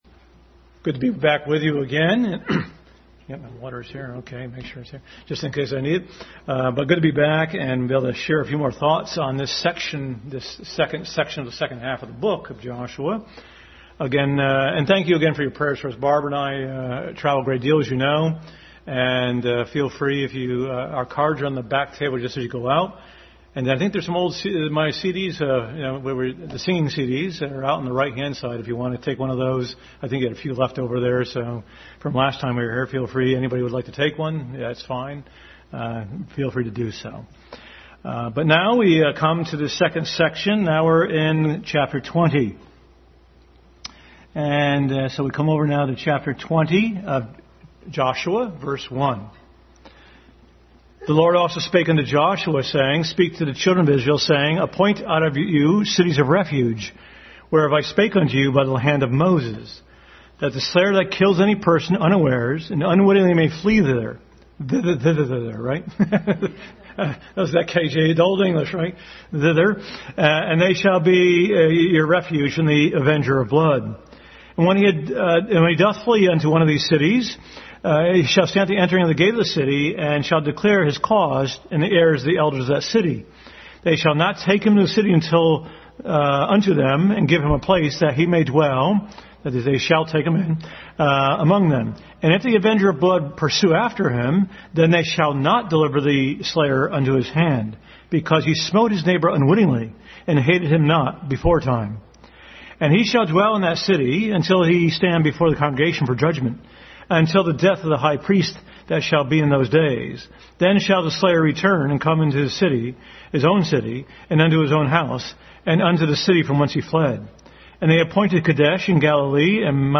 Joshua 20-21 Overview Passage: Joshua 29 & 21 Service Type: Family Bible Hour Family Bible Hour message.